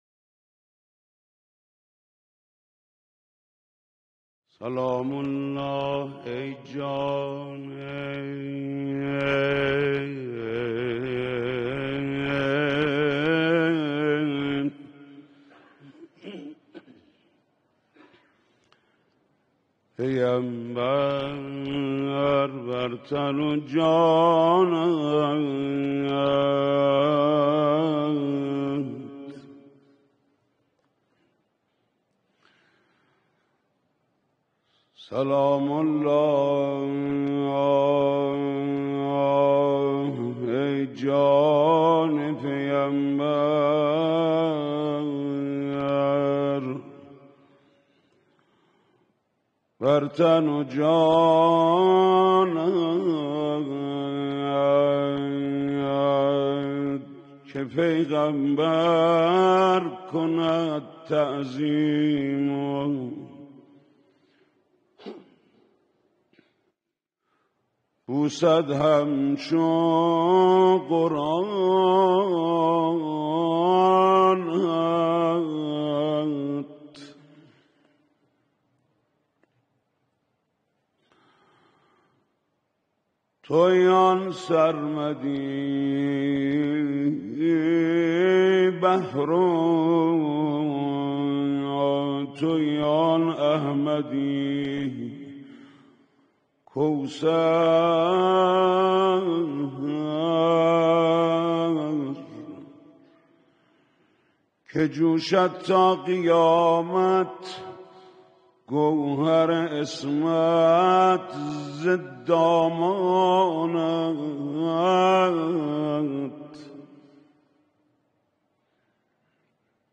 مداحی محمود کریمی در سومین شب مراسم فاطمیه در حسینیه امام (ره) - تسنیم
محمود کریمی مداح آیینی کشور در سومین شب عزاداری حضرت فاطمه سلام‌الله علیها در حضور رهبر معظم انقلاب به مداحی پرداخت.